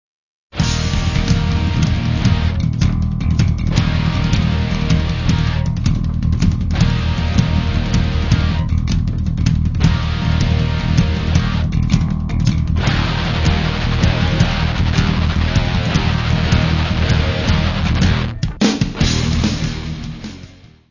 Music Category/Genre:  World and Traditional Music